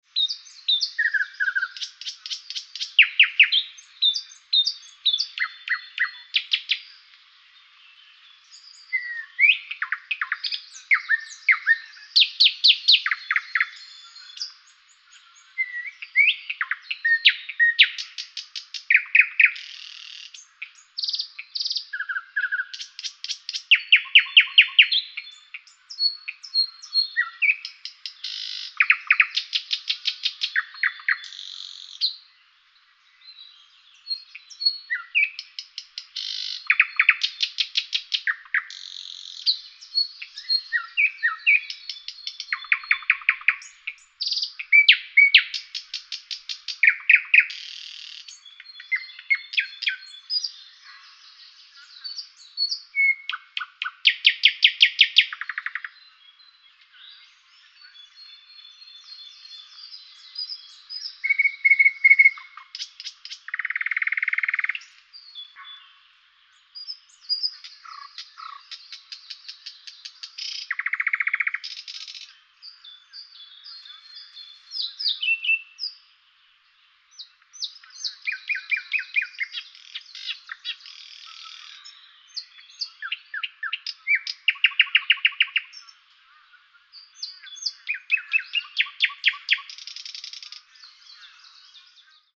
جلوه های صوتی
دانلود صدای بلبل از ساعد نیوز با لینک مستقیم و کیفیت بالا